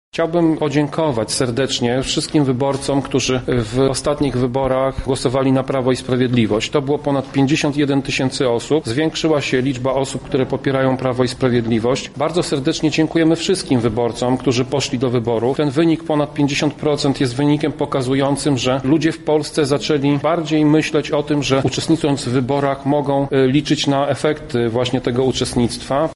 Wyniki skomentował szef klubu radnych PiS w Lublinie Tomasz Pitucha: